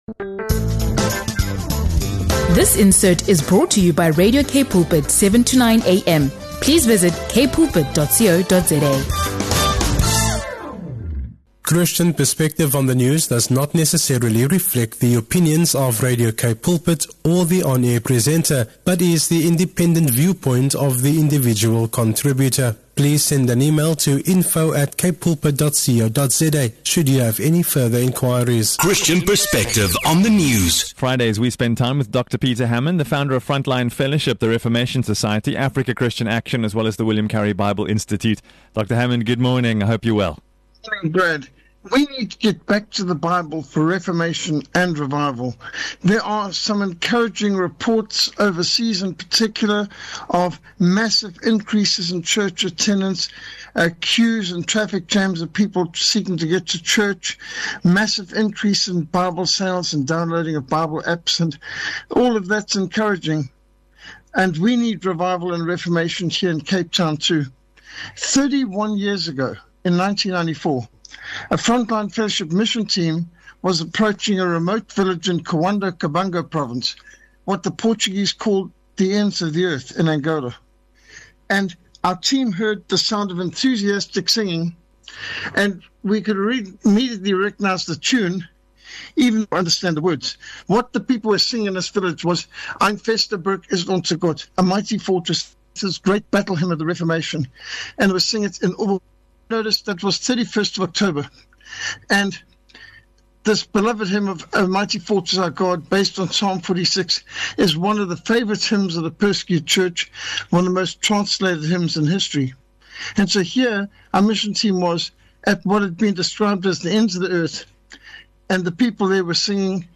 This discussion revisits the profound impact of the Protestant Reformation, sparked by Martin Luther on October 31, 1517.